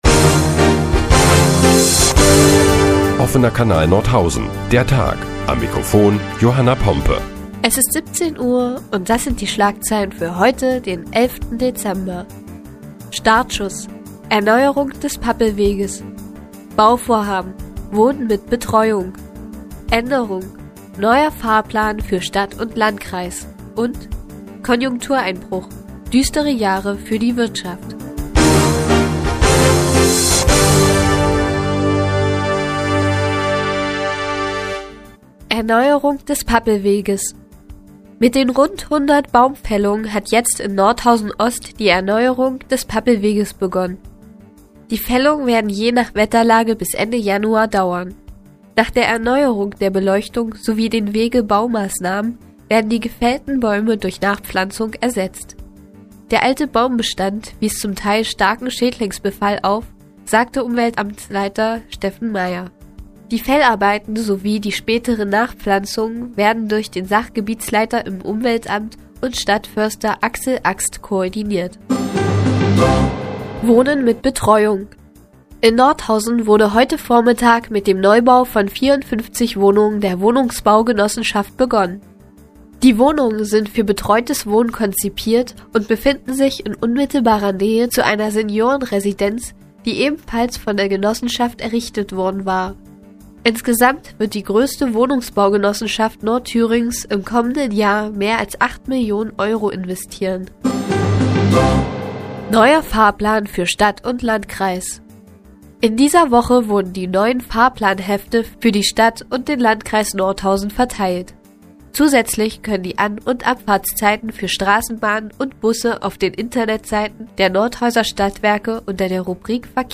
Die tägliche Nachrichtensendung des OKN ist nun auch in der nnz zu hören. Heute geht es unter anderem um die Erneuerung des Pappelweges und neue Fahrpläne für die Stadt und den Landkreis Nordhausen.